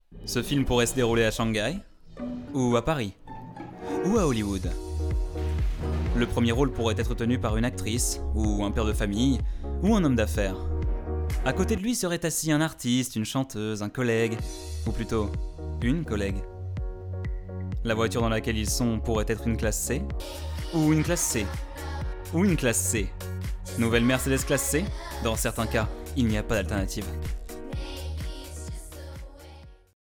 Extrait Voice Cover Storage War
12 - 30 ans - Baryton Ténor